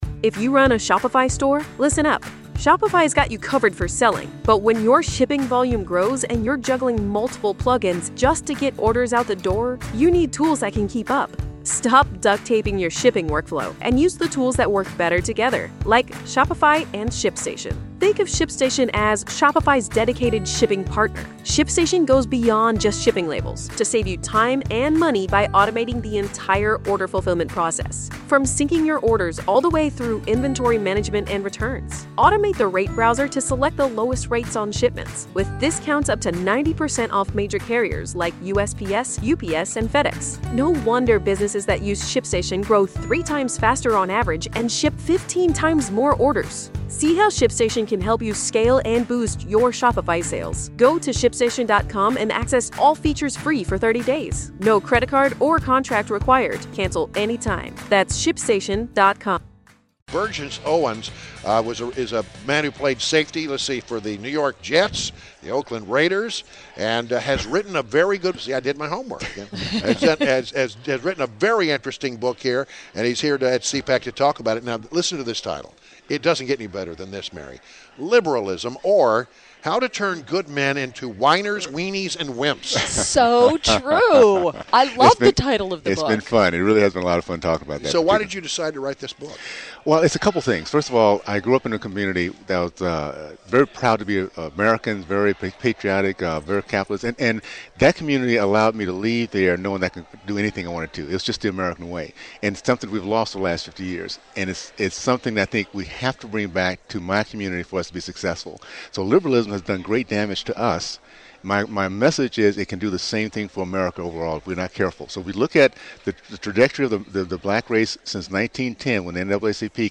WMAL Interview - BURGESS OWENS - 02.23.17